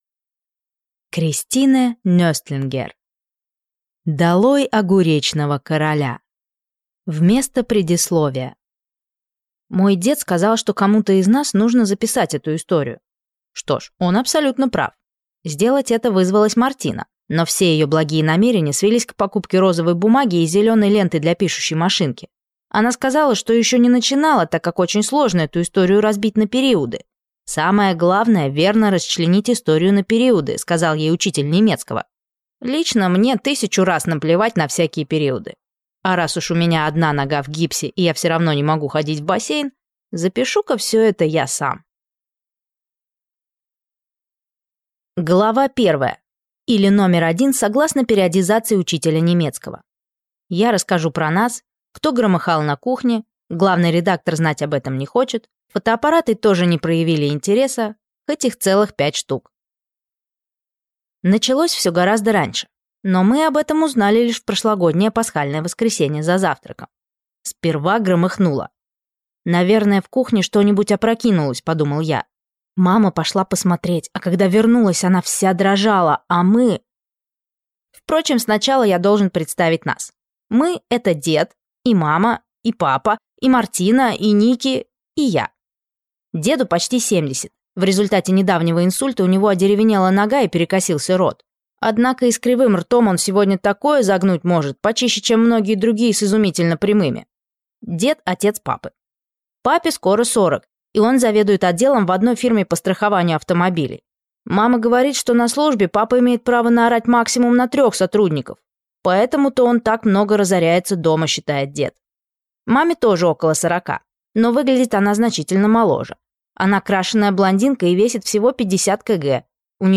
Аудиокнига Долой огуречного короля | Библиотека аудиокниг
Прослушать и бесплатно скачать фрагмент аудиокниги